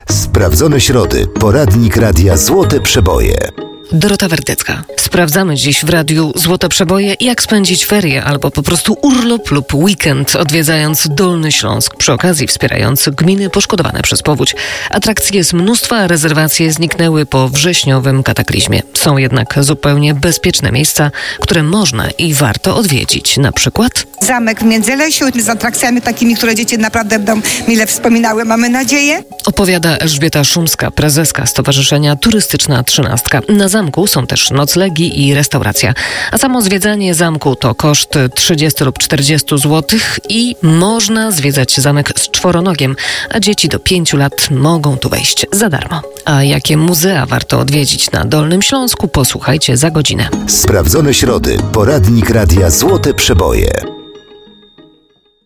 Odpowiedzi w audycji "Sprawdzone środy" na antenie Radia Złote Przeboje.